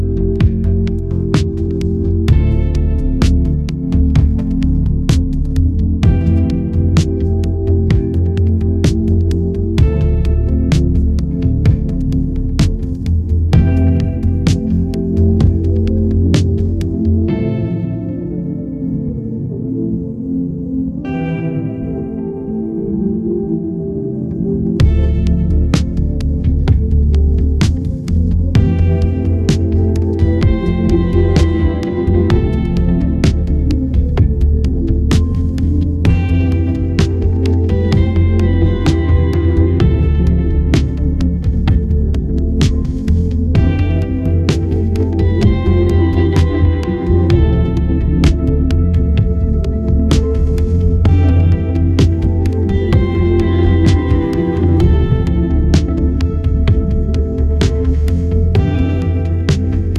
infinifi - infinifi plays gentle lofi music in the background indefinitely